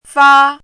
chinese-voice - 汉字语音库
fa1.mp3